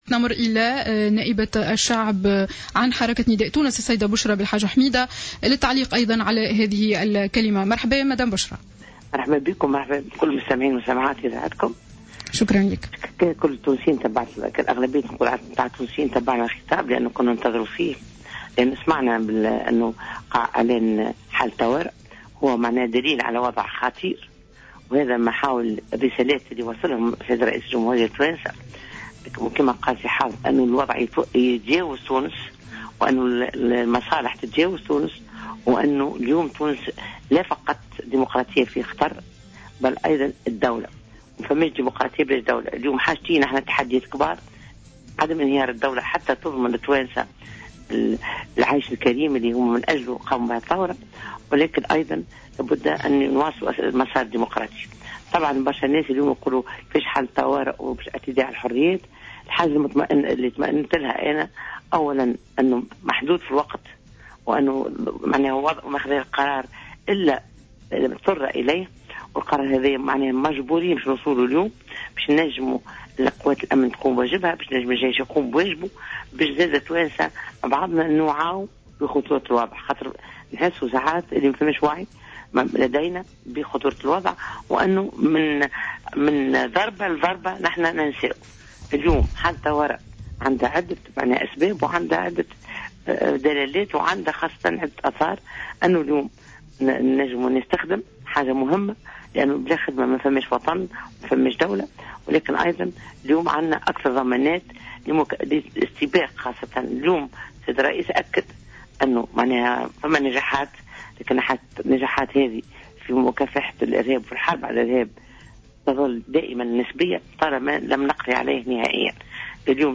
اعتبرت بشرى بلحاج حميدة القيادية في حزب نداء تونس في تصريح لجوهرة أف أم اليوم السبت 04 جويلية 2015 أن إعلان حالة الطوارئ هو قرار اضطراري يوحي بخطورة الوضع الذي تعيشه البلاد.